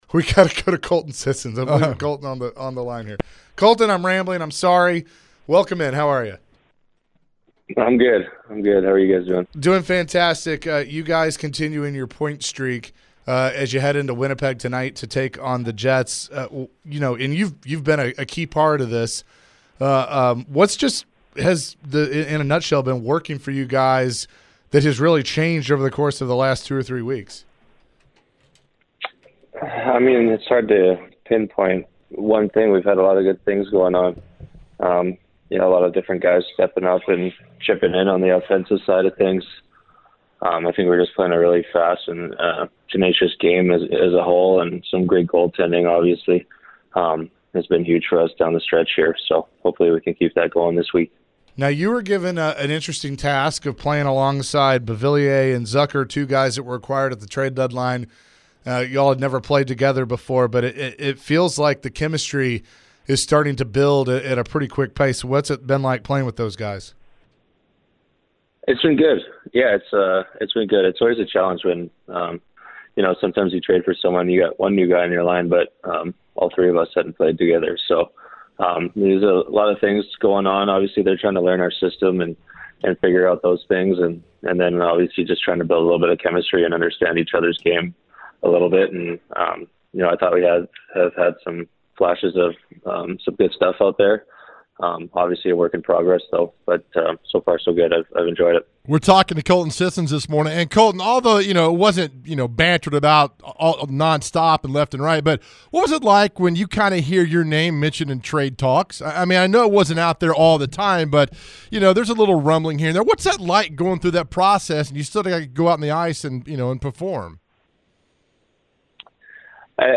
Colton Sissons Interview (03-13-24)
Predators center Colton Sissons joins the Chase & Big Joe Show ahead of their game tonight against the Winnipeg Jets. Sissons talks about what led to the team's recent success.